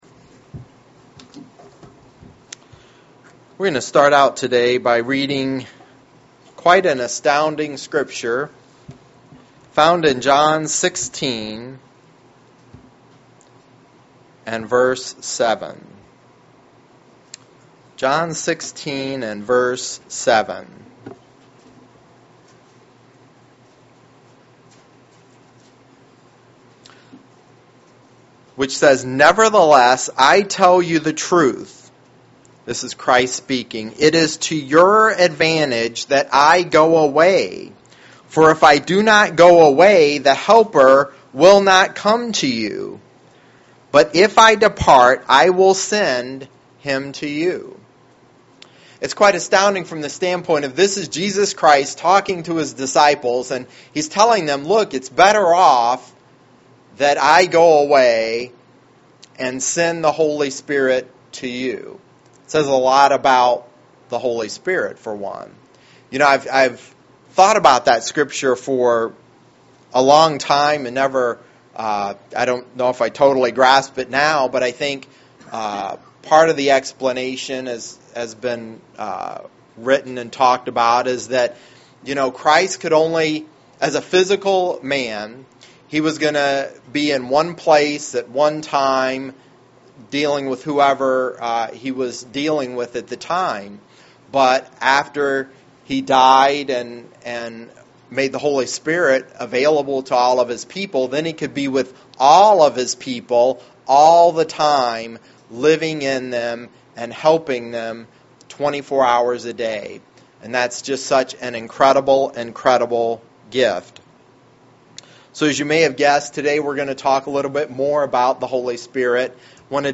Sermons
Given in Lansing, MI